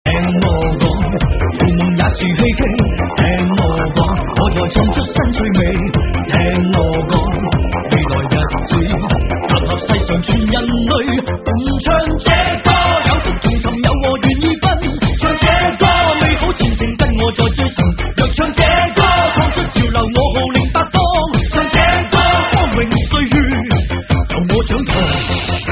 华语歌曲
粤语